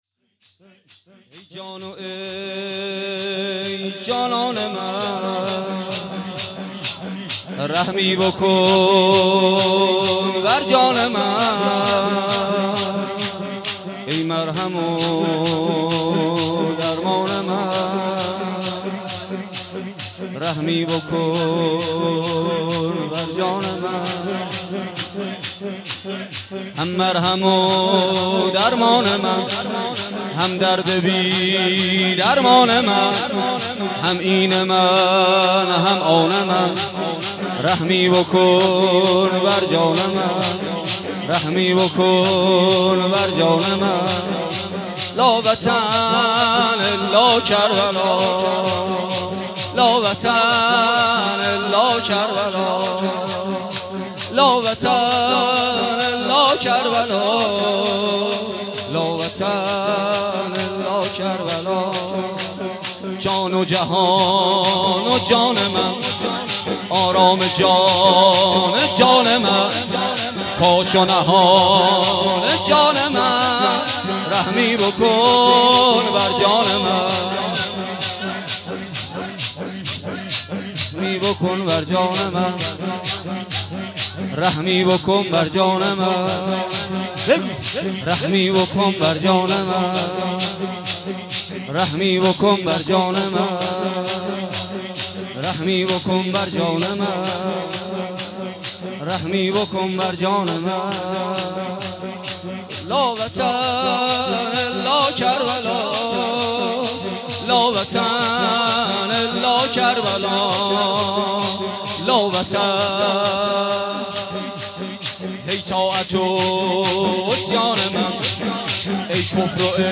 در زینبیه شهدای گمنام برگزار شد
روضه
شور